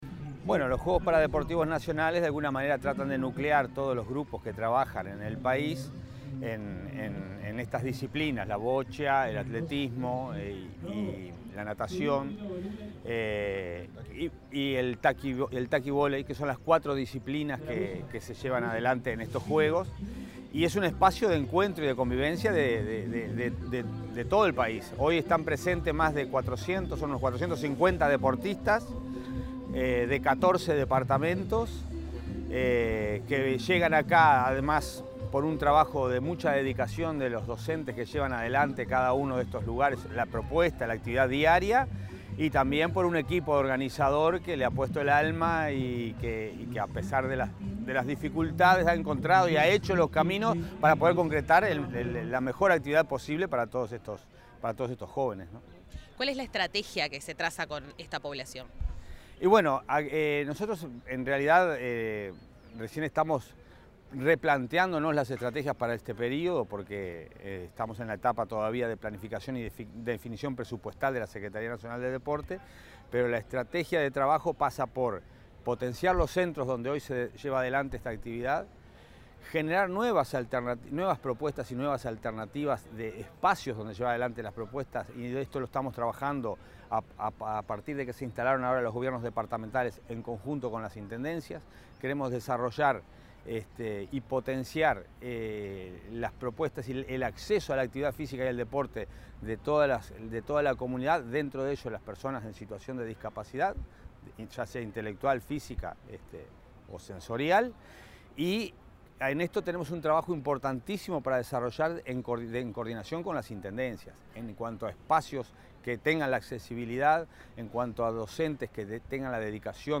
Declaraciones del secretario nacional del Deporte, Alejandro Pereda
En la jornada inaugural de los Juegos Paradeportivos Nacionales 2025, el secretario nacional del Deporte, Alejandro Pereda, informó acerca de este